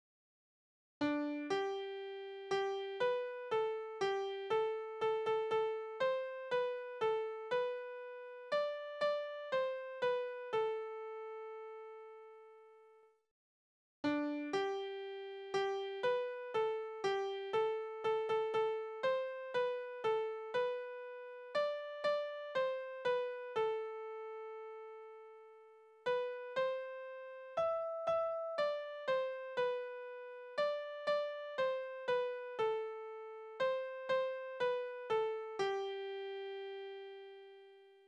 Tonart: G-Dur Taktart: 3/4
Besetzung: instrumental